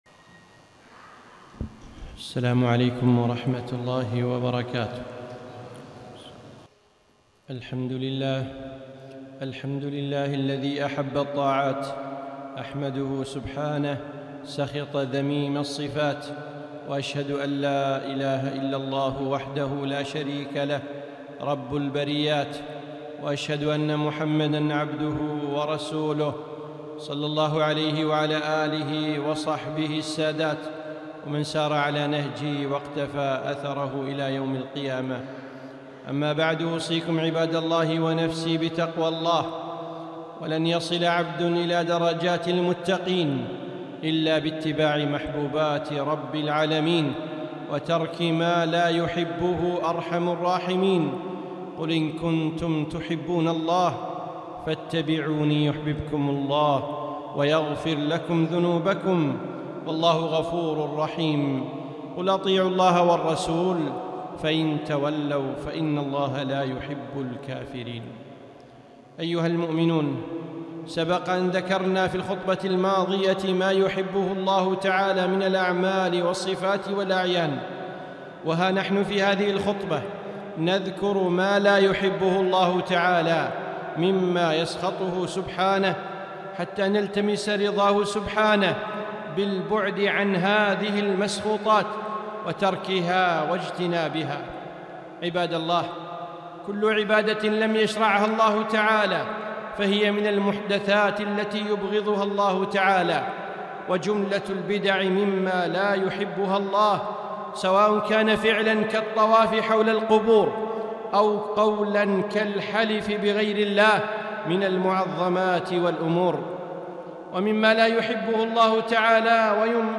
خطبة - ما لا يحبه الله تعالى